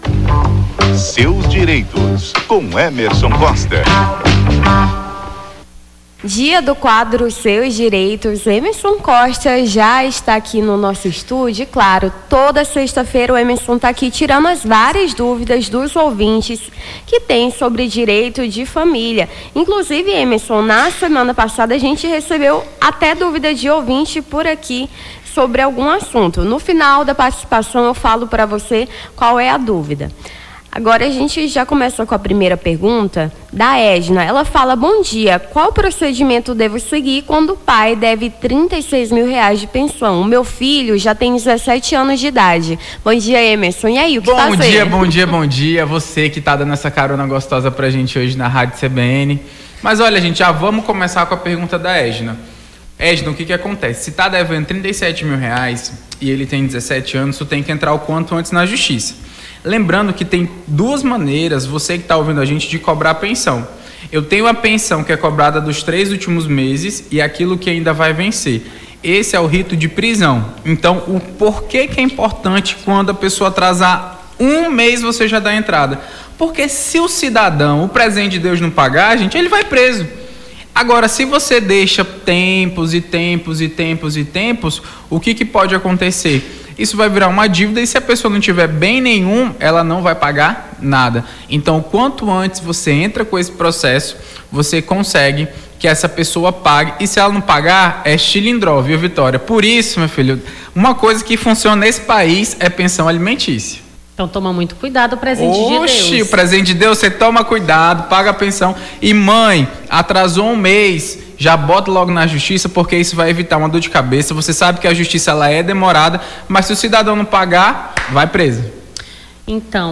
AO VIVO: Confira a Programação
Seus Direitos: advogado tira-dúvidas dos ouvintes sobre o direito de família